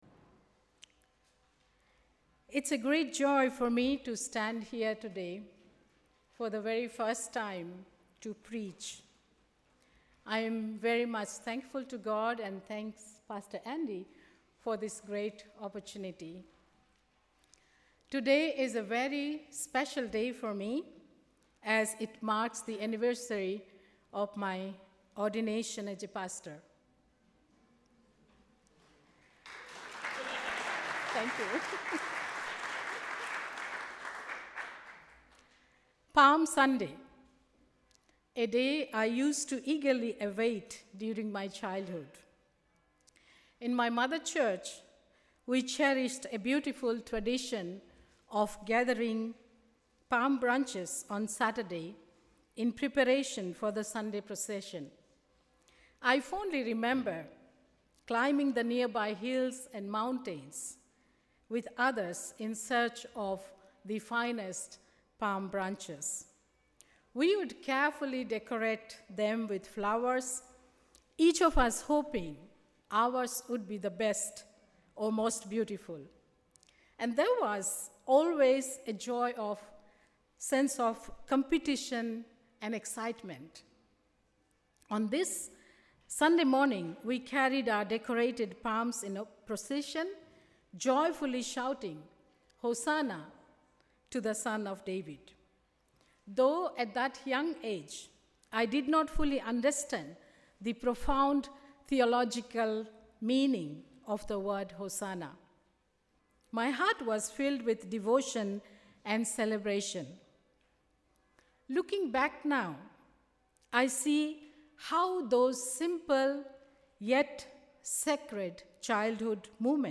Sermon: ‘Hosanna’